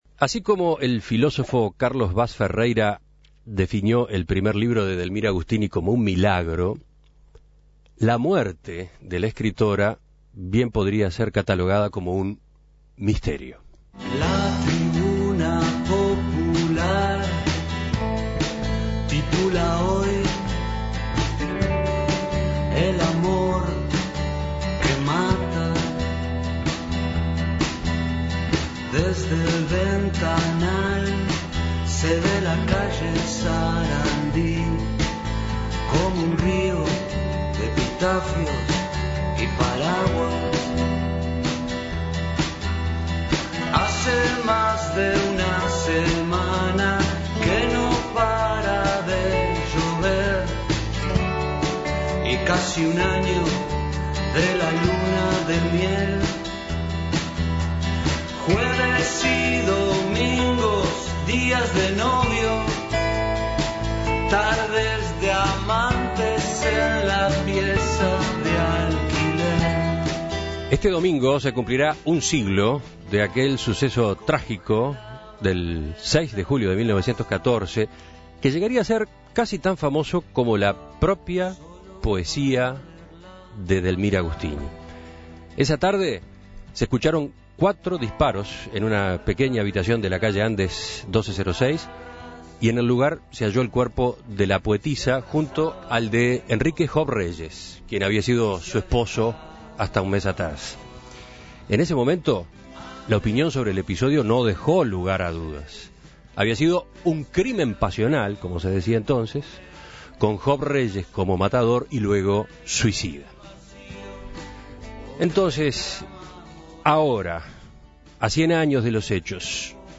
Escuche la entrevista Descargar Audio no soportado La transcripción de esta entrevista está disponible aquí.